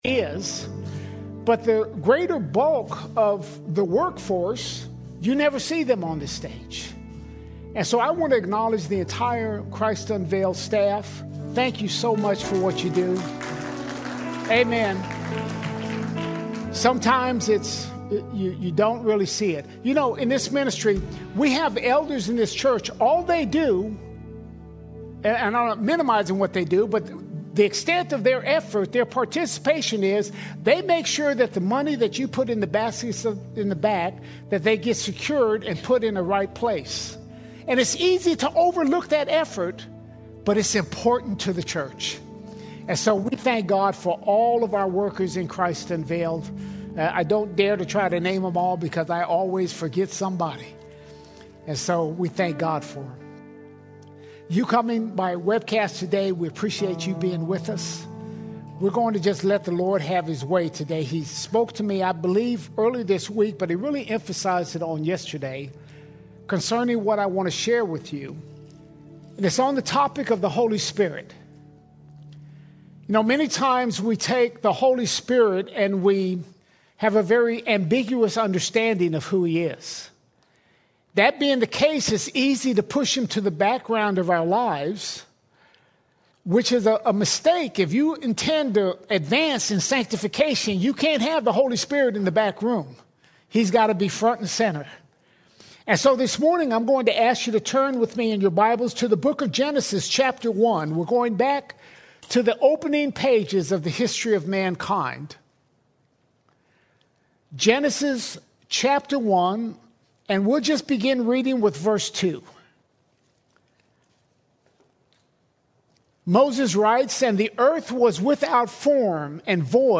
2 March 2026 Series: Sunday Sermons All Sermons The Holy Spirit The Holy Spirit Often the Holy Spirit is treated as the forgotten part of the Godhead, yet from the beginning He has been active.